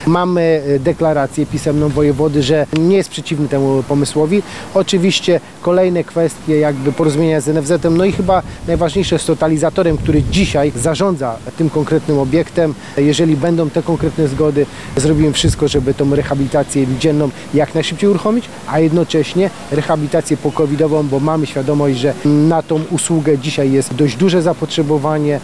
Lecznica przy ul. Narutowicza wstrzymała już przyjmowanie covidowych pacjentów jednocześnie pozostając w gotowości do pracy. To w związku ze zmniejszeniem się liczby chorych wymagających hospitalizacji z powodu zakażenia koronawirusem – mówi wiceprezydent Radomia Jerzy Zawodnik, odpowiedzialny w mieście za służbę zdrowia.